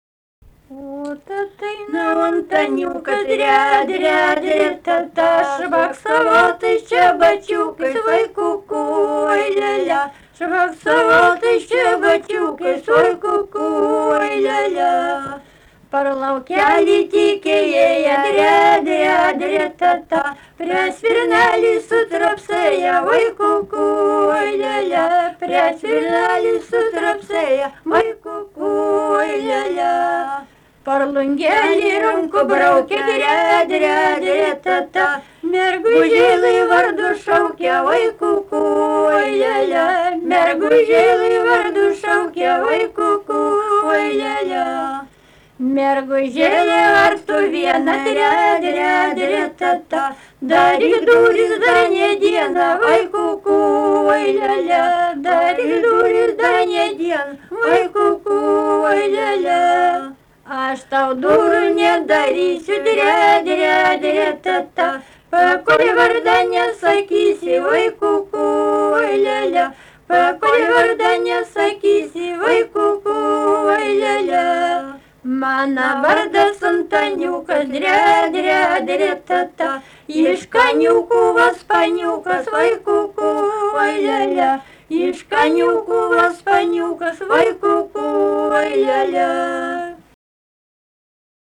daina, vestuvių
Erdvinė aprėptis Suginčiai
Atlikimo pubūdis vokalinis